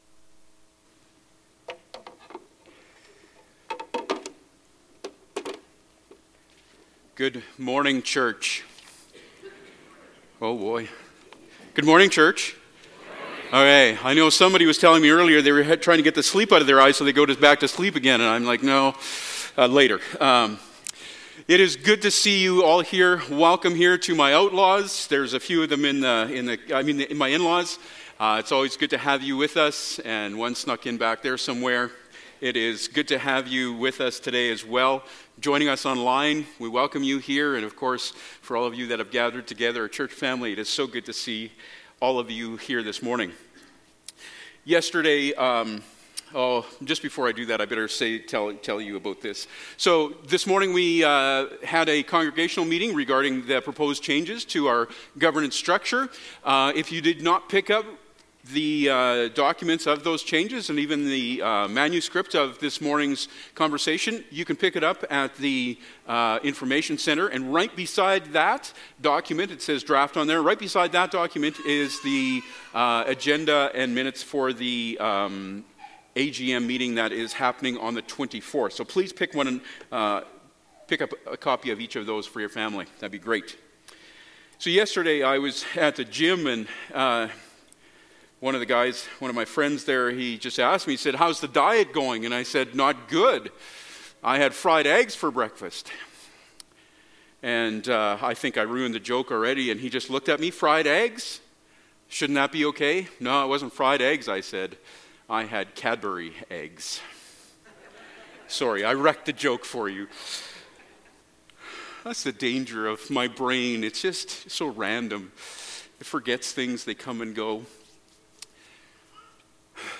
A Call to Repentance Passage: Amos 8 Service Type: Sunday Morning Topics